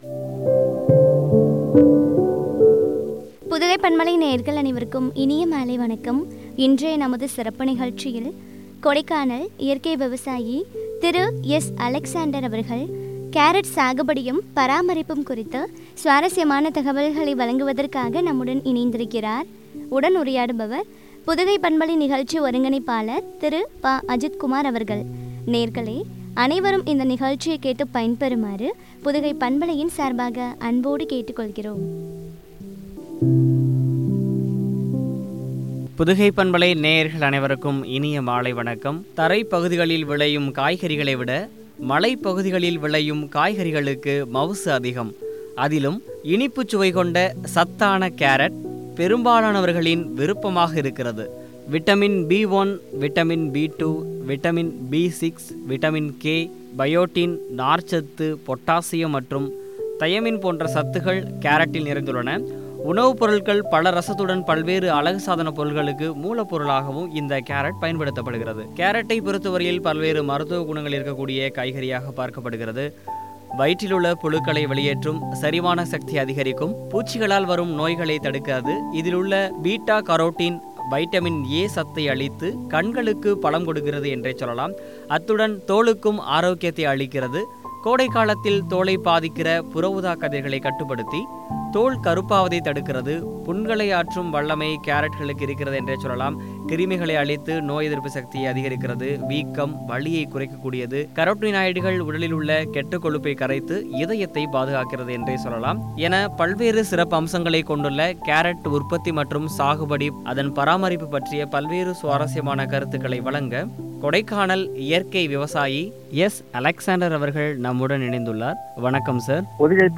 பராமரிப்பும் குறித்து வழங்கிய உரையாடல்.